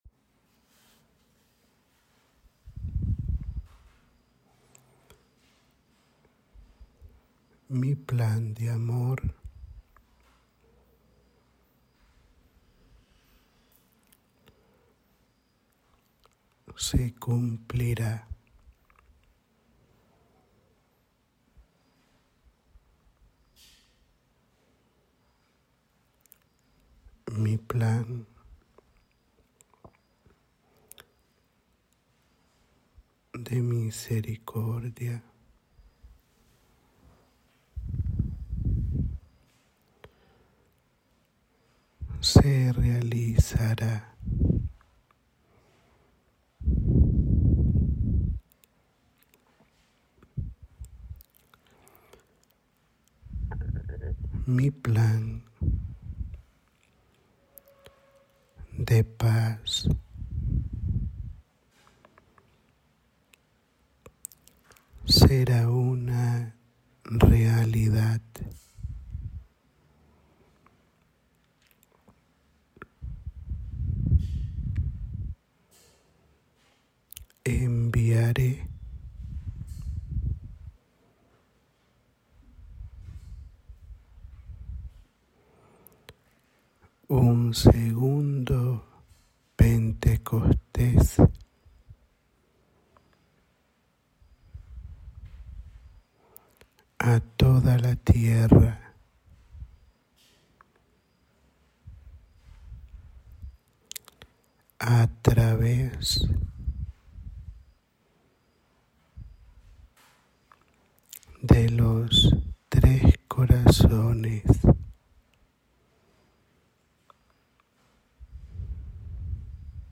Audio da Mensagem